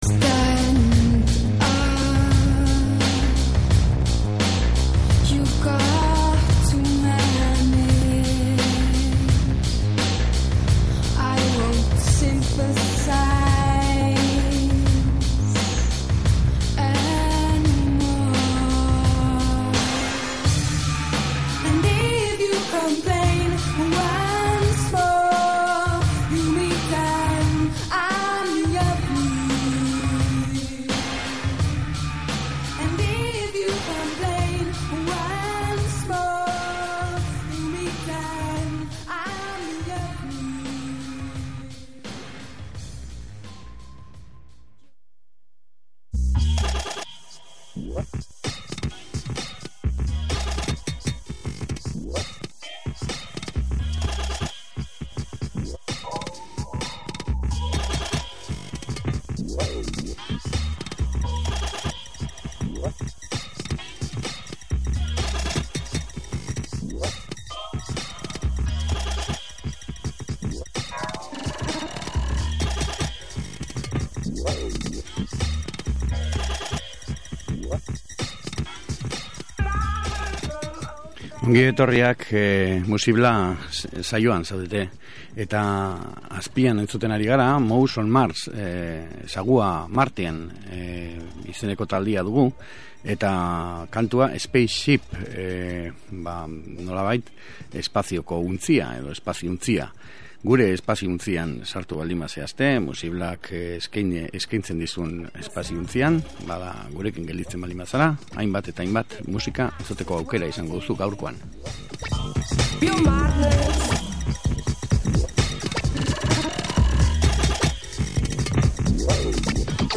erritmo sinkopatuekin hasi